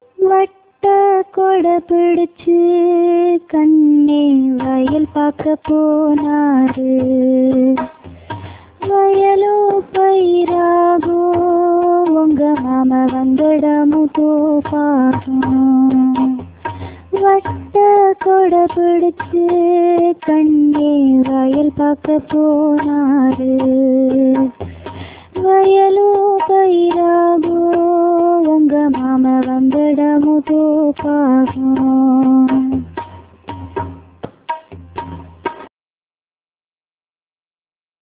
(தாலாட்டுப் பாடல்)